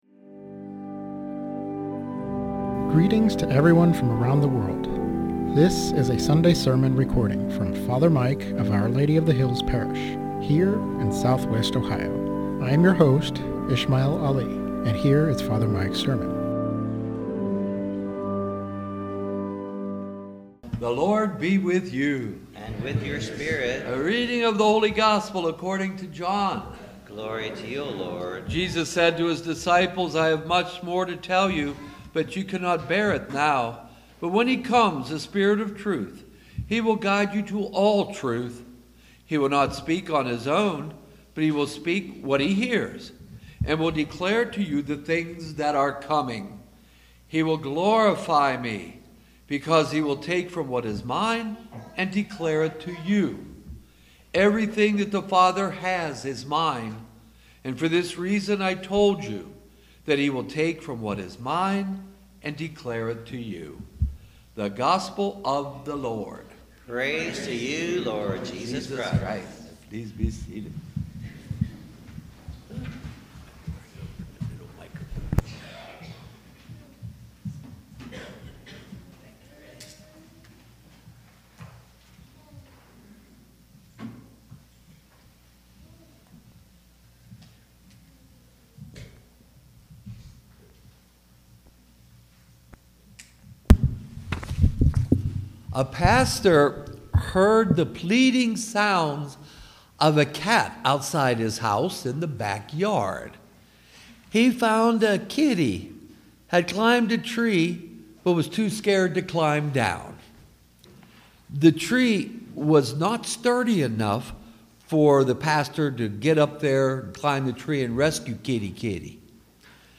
Sermon on Holy Trinity Sunday - Our Lady of the Hills - Church